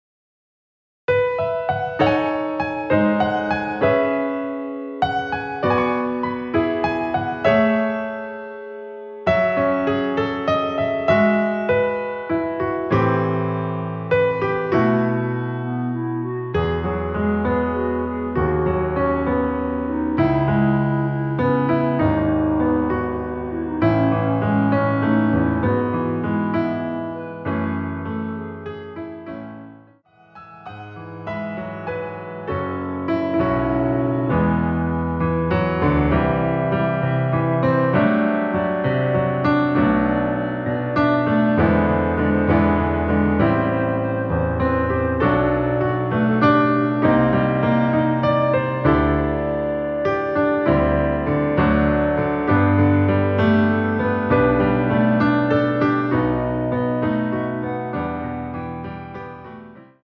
앞부분30초, 뒷부분30초씩 편집해서 올려 드리고 있습니다.
곡명 옆 (-1)은 반음 내림, (+1)은 반음 올림 입니다.
(멜로디 MR)은 가이드 멜로디가 포함된 MR 입니다.